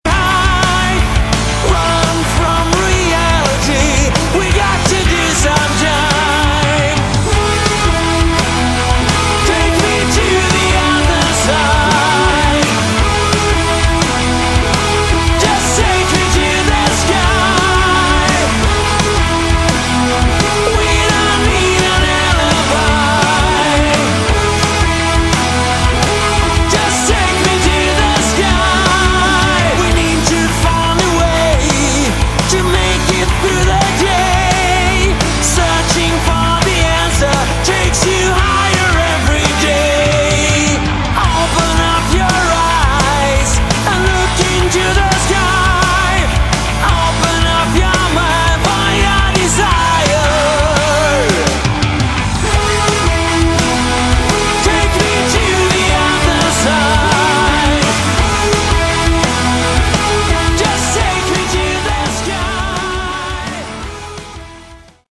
Category: Melodic Rock
vocals
guitar
bass
drums
keyboards